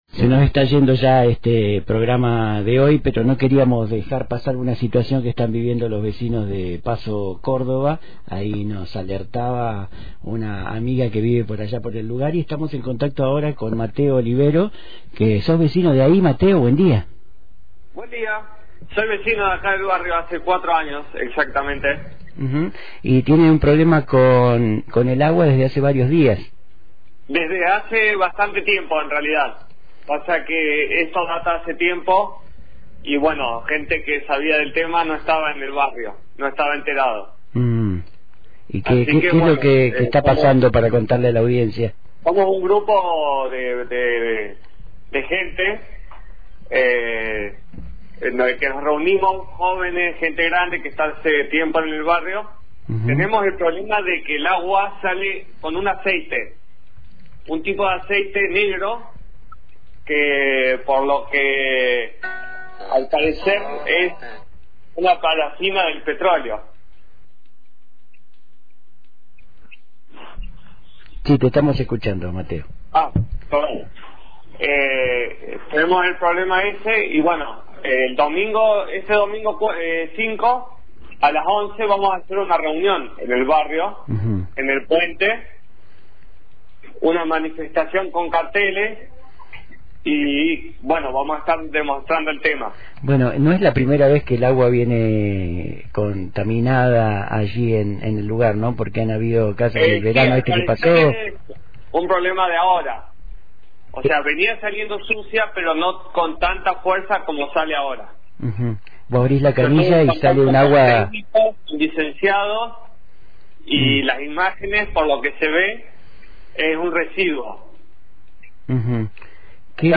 en diálogo con la radio